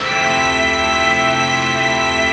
ATMOPAD20.wav